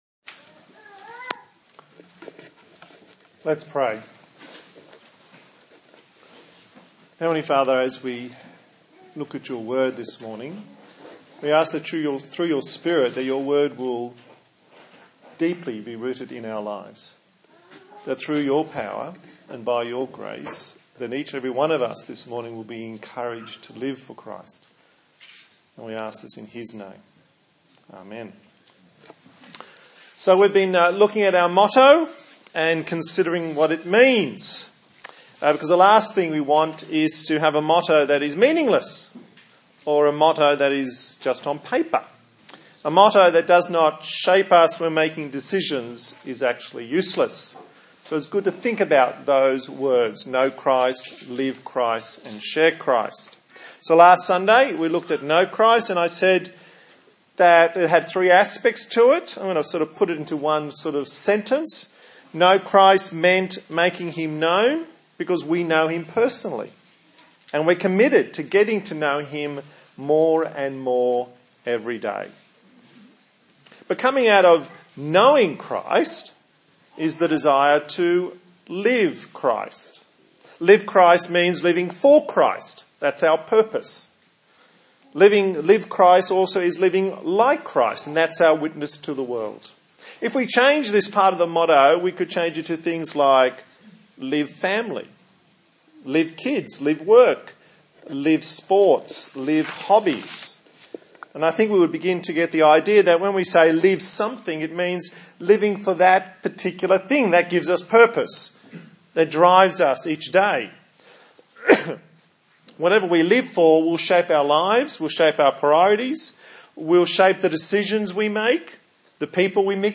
21/05/2017 Live Christ Preacher
Galatians 2:11-20 Service Type: Sunday Morning A sermon on the TPC motto